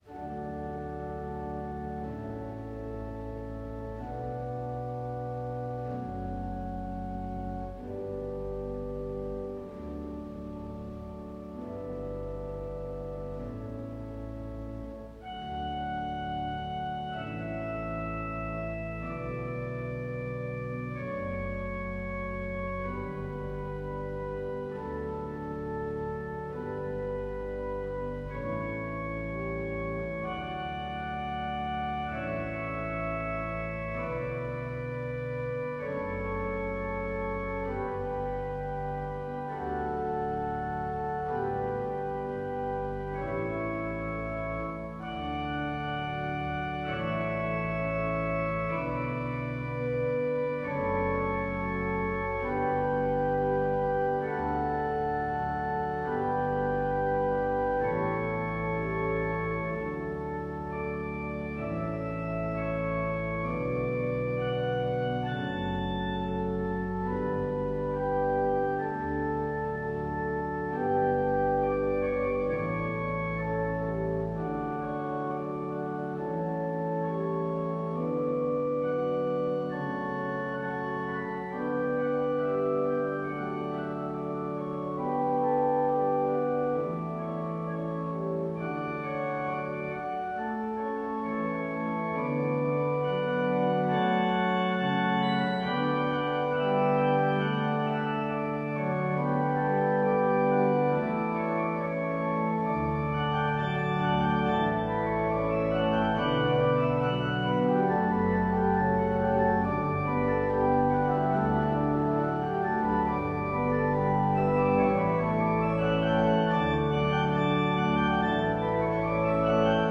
Волшебная музыка на живом органе